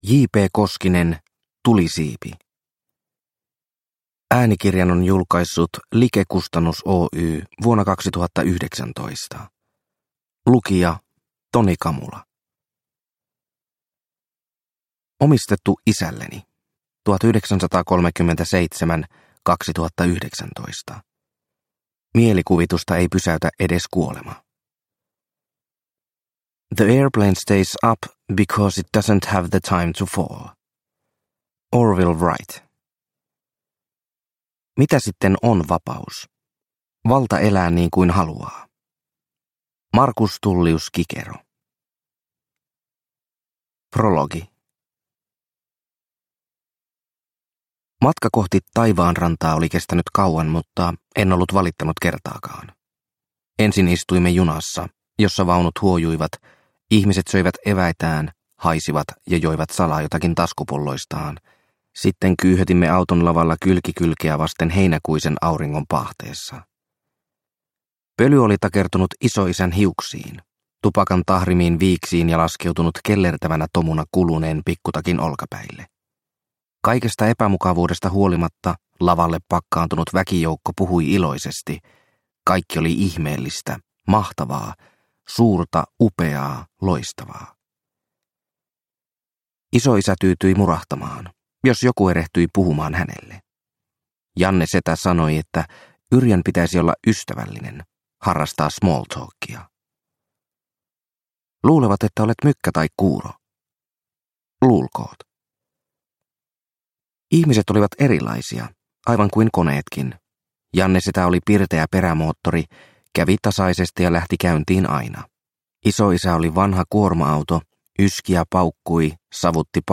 Tulisiipi – Ljudbok – Laddas ner